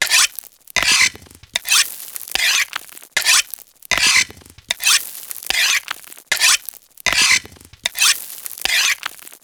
sharpen.wav